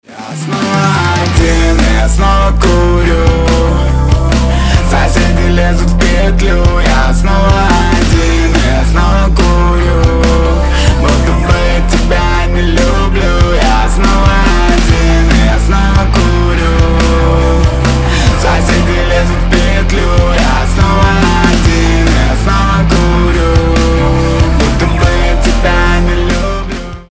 • Качество: 320, Stereo
гитара
мужской голос
Драйвовые